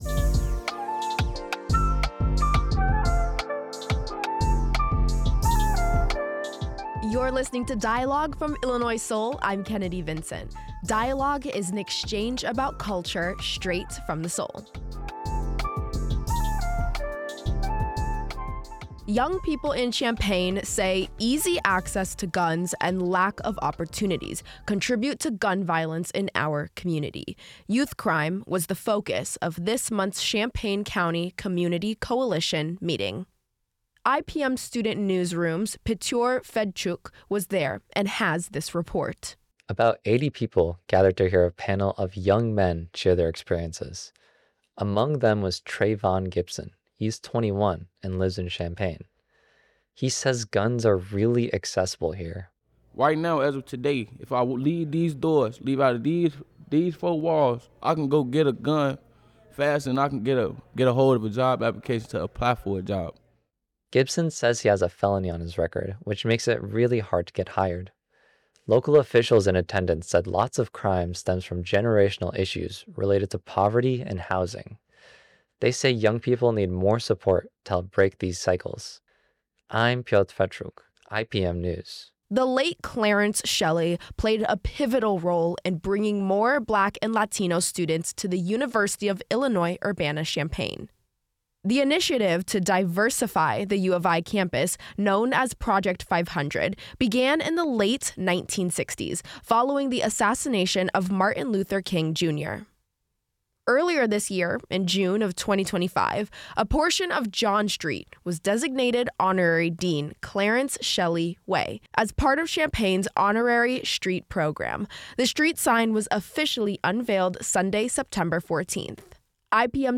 Community members and officials highlight issues youth face like gun violence, while others honor an educator through a street unveiling and two classmates reflect on school integration decades later.